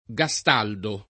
gastaldo [ g a S t # ldo ]